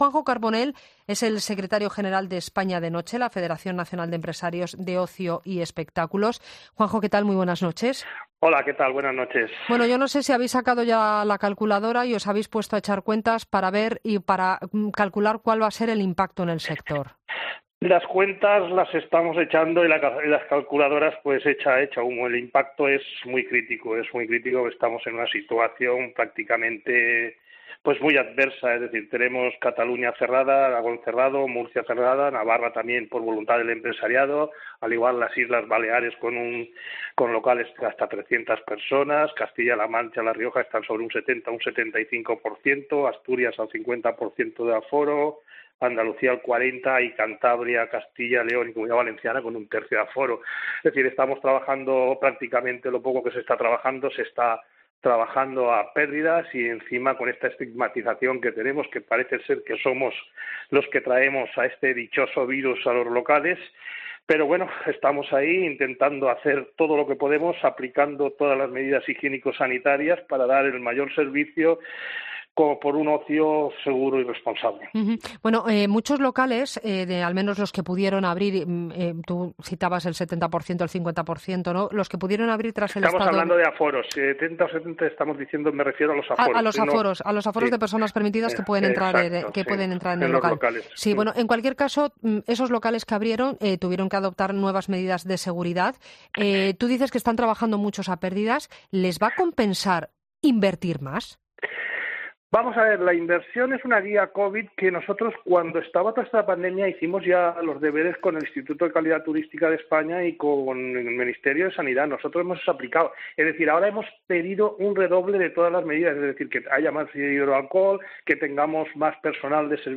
ha sido entrevistado en La Linterna para analizar la situación del ocio nocturno con la pandemia del coronavirus, y sobre todo, el impacto económico que el virus va a tener.